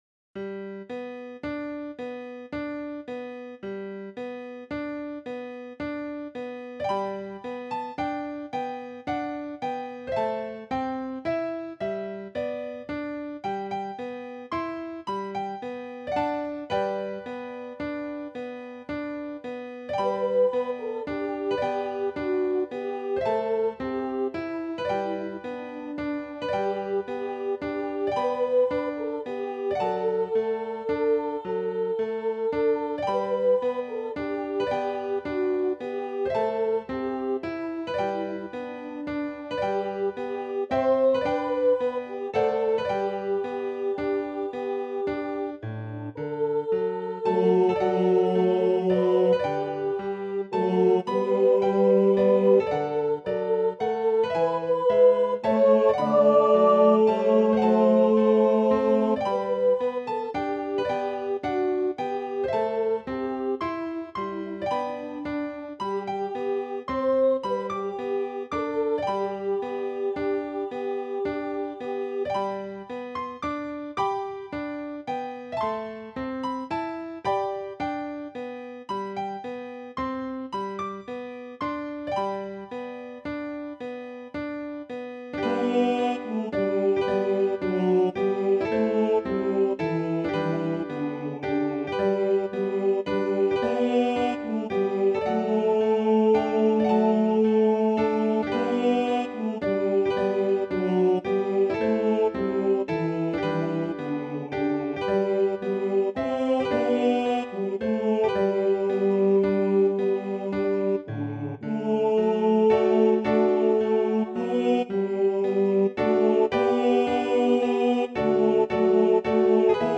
Voicing/Instrumentation: SATB We also have other 5 arrangements of " Softly and Tenderly, Jesus is Calling ".